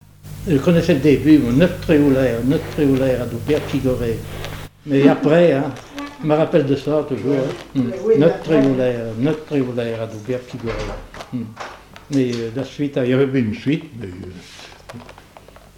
danse : branle : grenoïe
accordéon diatonique
Pièce musicale inédite